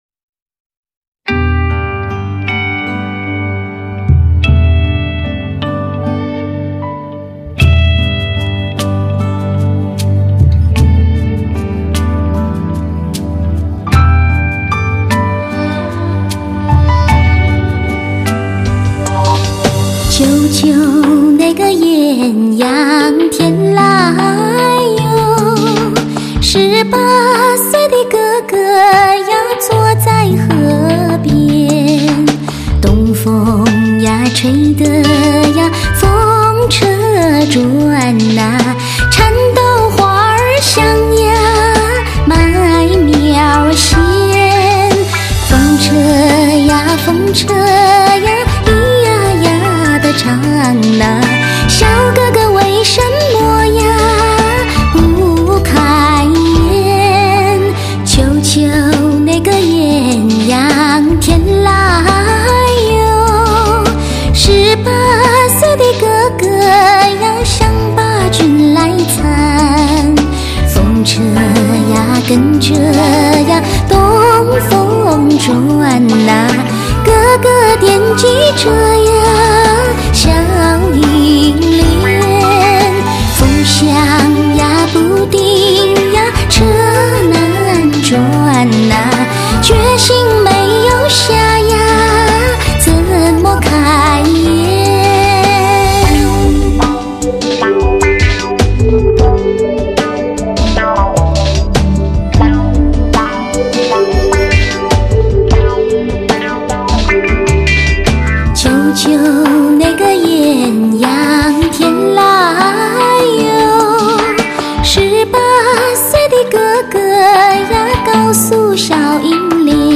最经典民歌，最怀旧旋律，